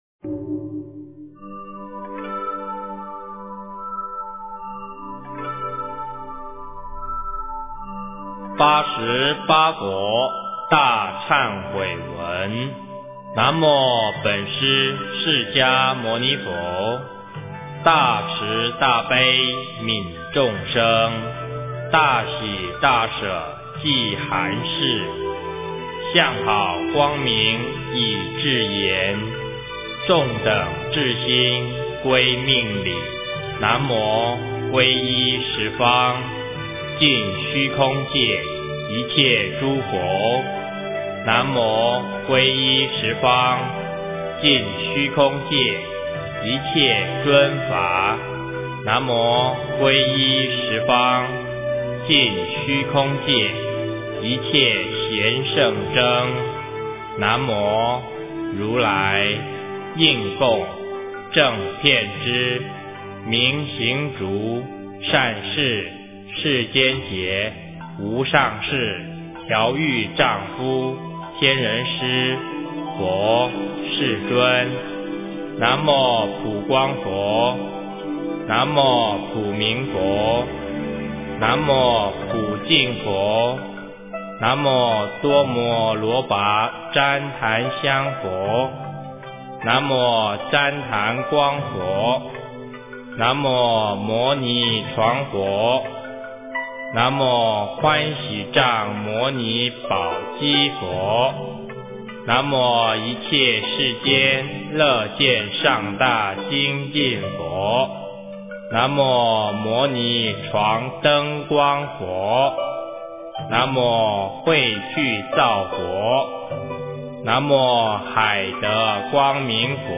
八十八佛大忏悔文 - 诵经 - 云佛论坛